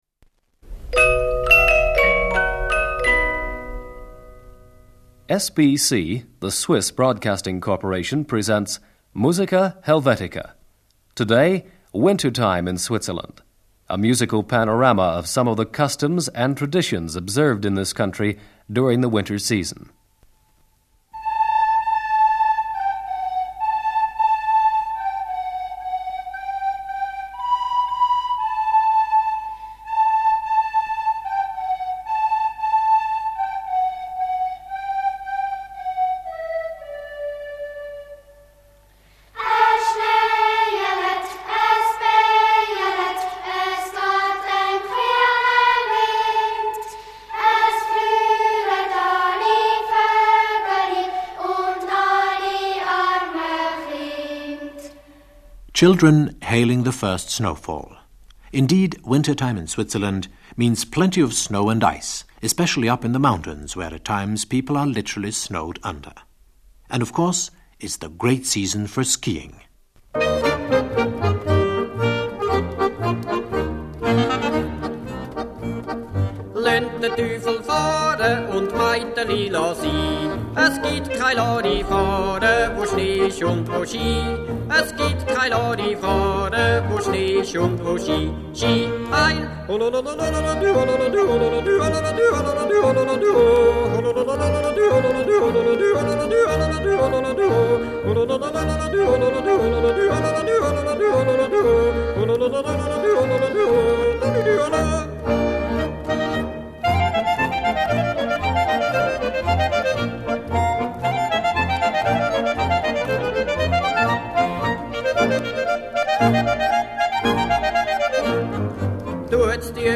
Swiss Folk Music. Wintertime in Switzerland.
Yodel.